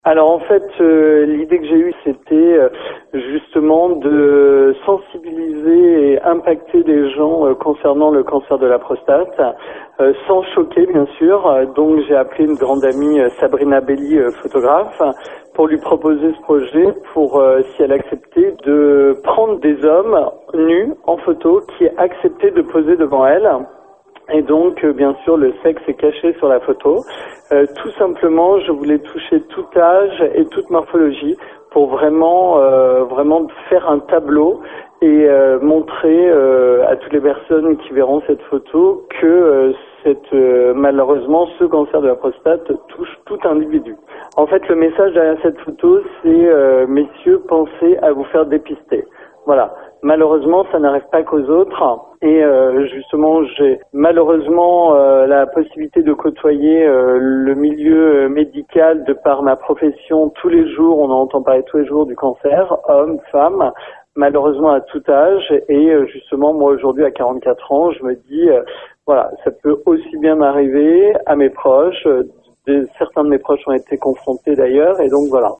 Il nous en parle :